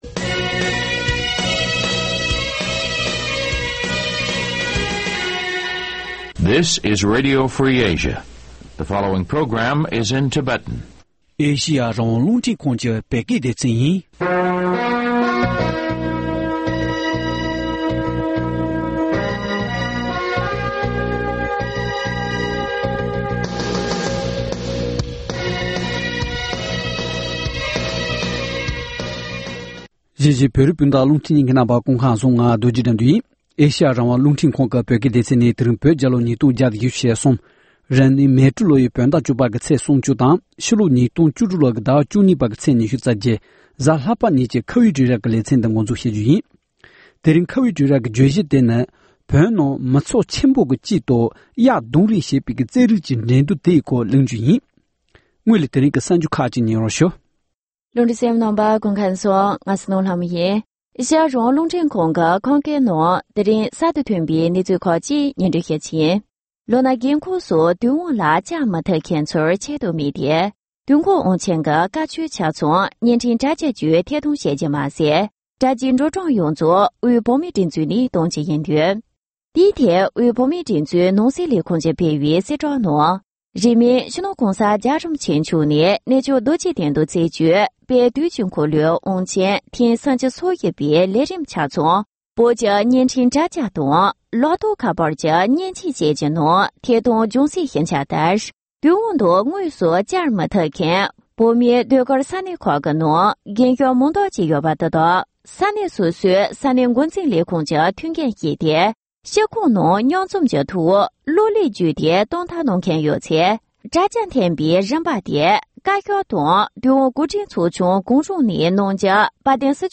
བགྲོ་གླེང་ཞུས་པ་ཞིག